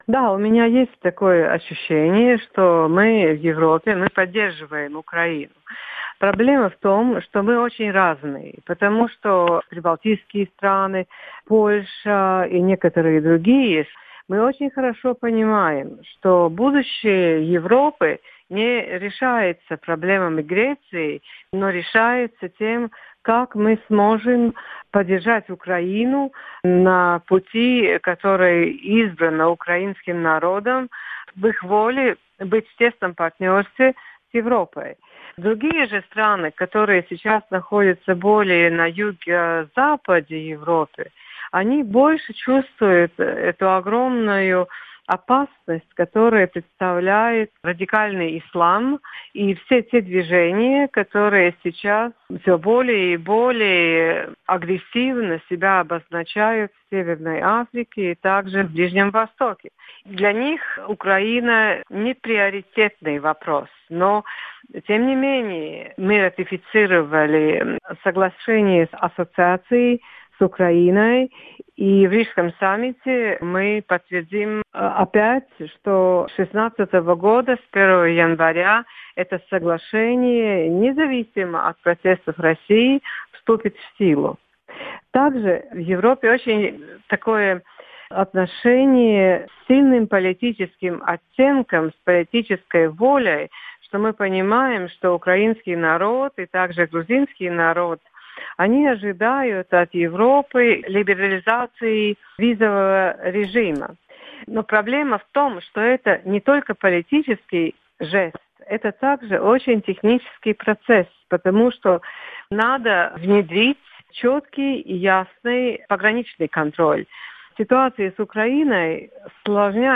Мы не наивны, – сказала в интервью Радио Свобода депутат Европейского парламента от Латвии Сандра Калниете.
Фрагмент итогового выпуска программы "Время Свободы"